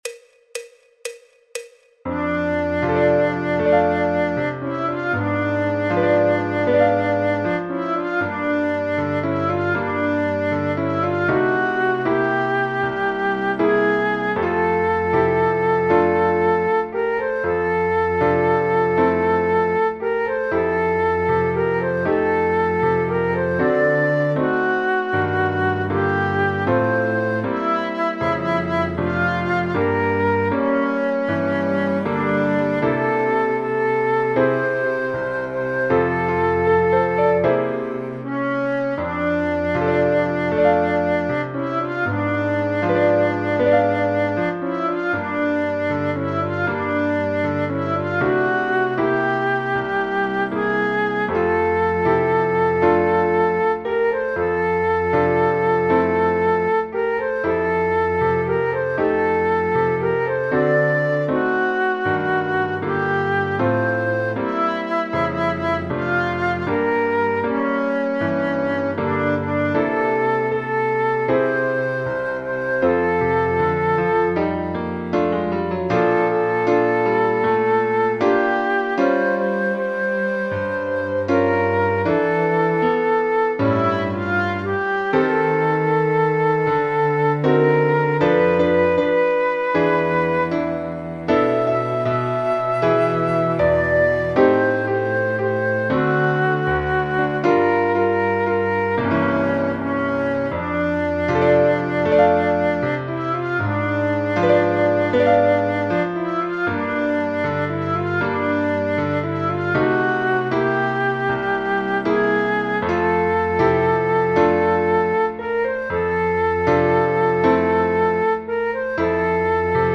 El MIDI tiene la base instrumental de acompañamiento.
in F Major Jazz Beginner Level
Flauta Dulce, Flauta Travesera
Fa Mayor
Jazz, Popular/Tradicional